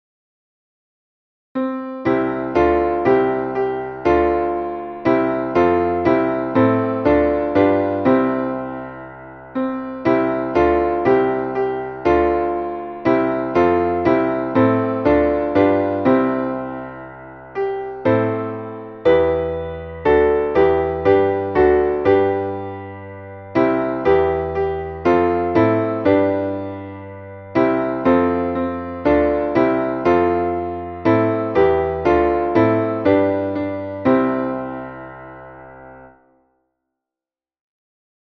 Traditionelles Weihnachtslied